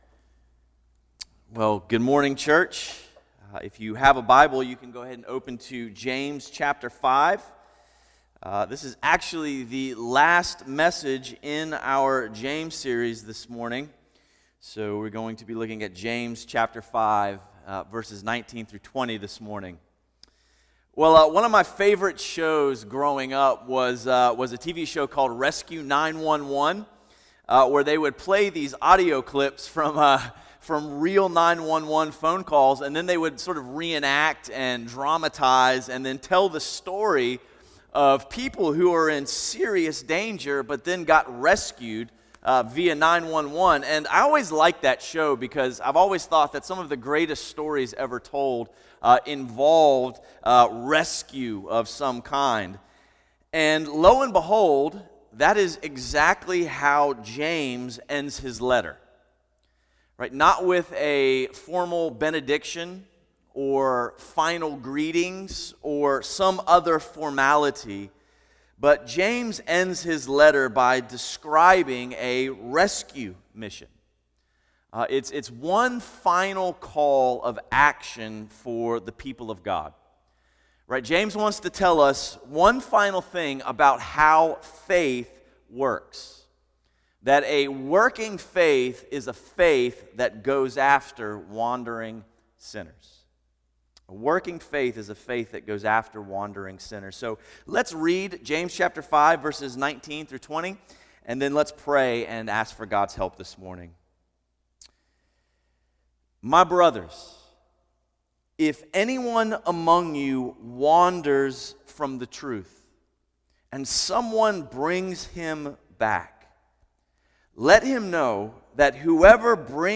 A message from the series "New Life in Jesus."